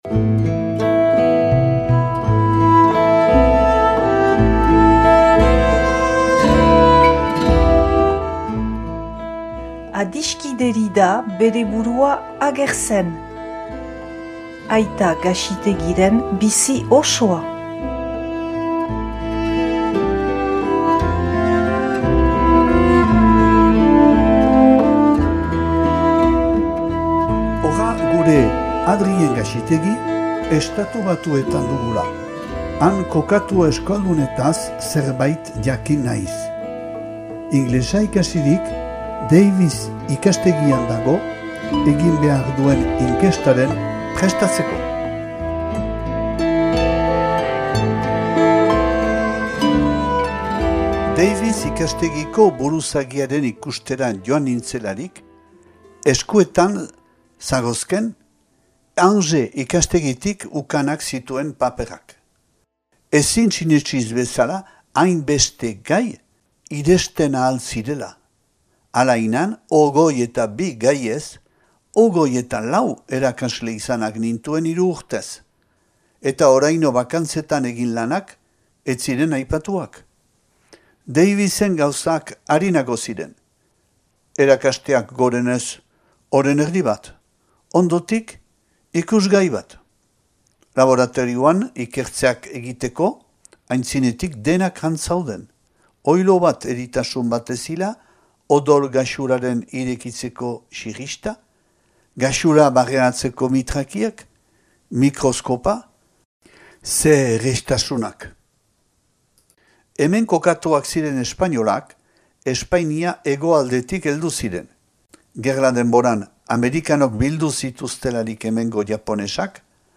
irakurketa bat dauzuegu eskaintzen